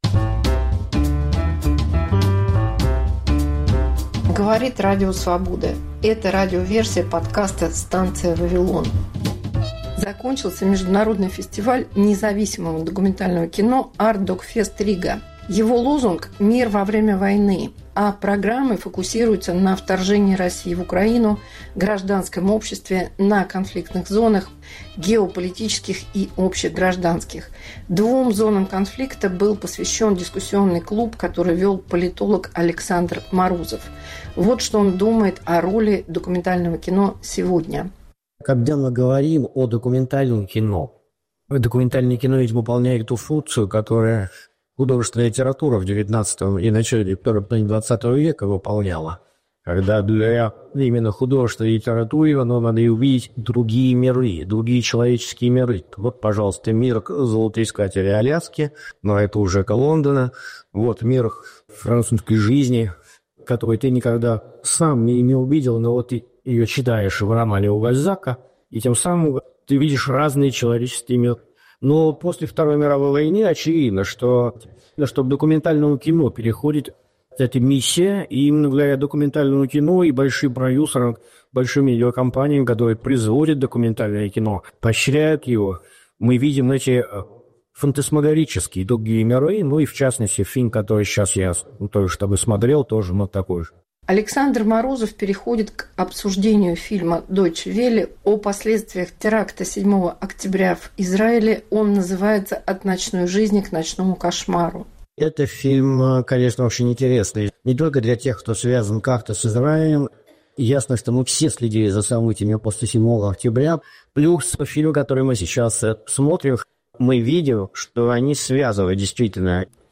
Дискуссия на Artdocfest/Riga.